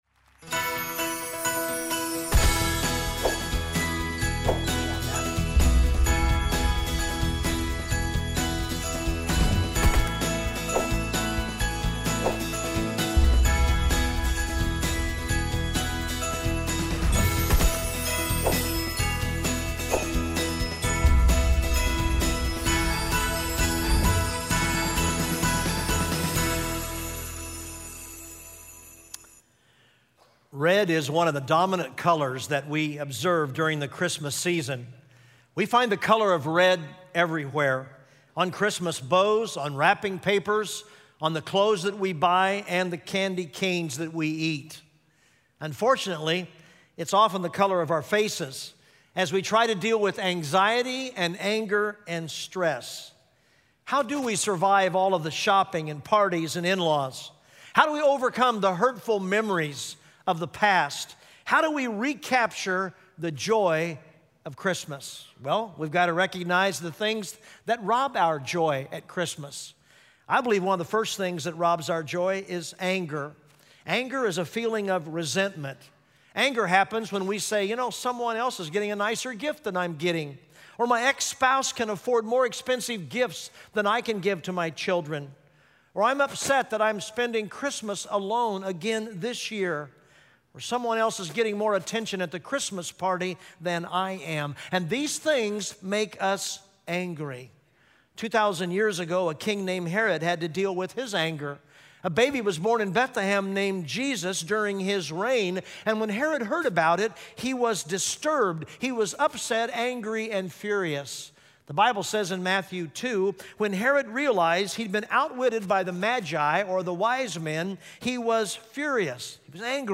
Colors Of Christmas: Red Christmas (Full Service)